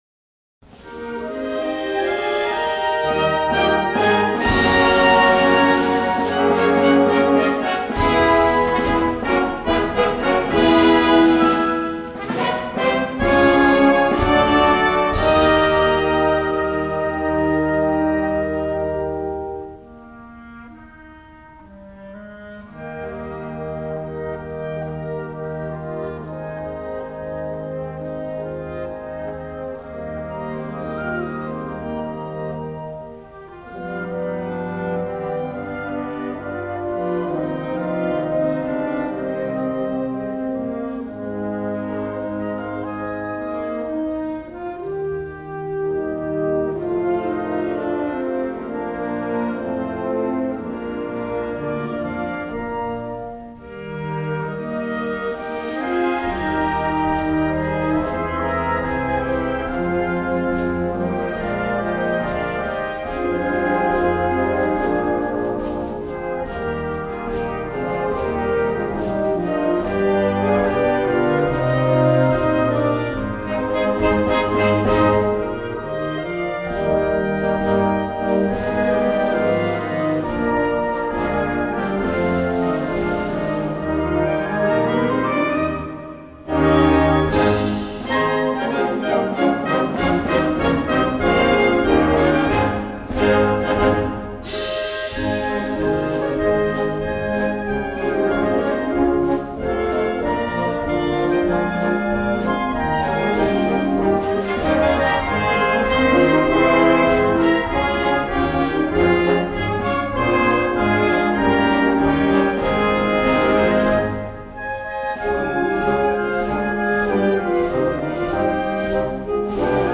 (วงโยวาทิต)